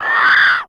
CREATURE_Squeel_04_mono.wav